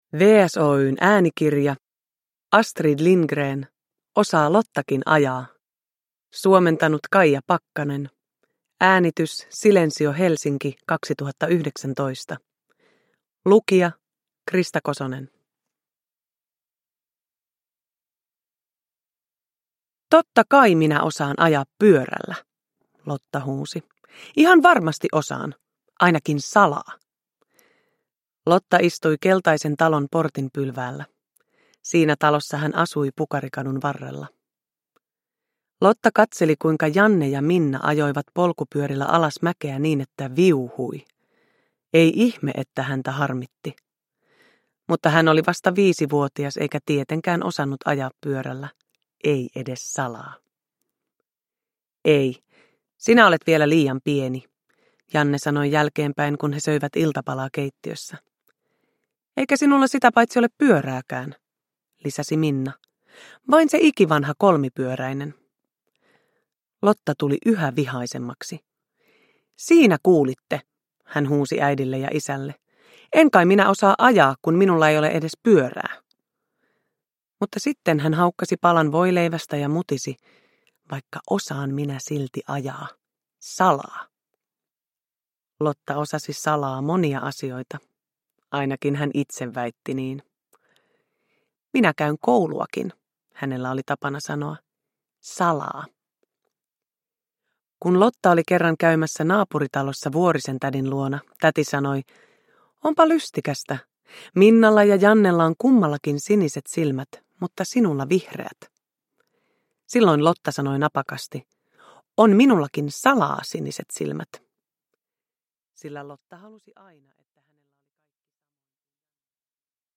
Osaa Lottakin ajaa – Ljudbok
Uppläsare: Krista Kosonen